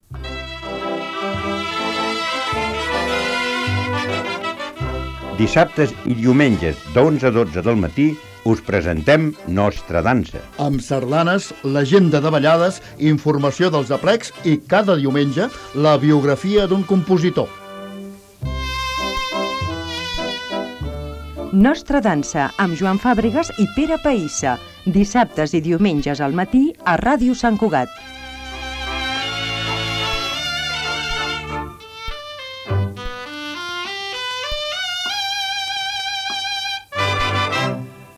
Falca de promoció del programa
Musical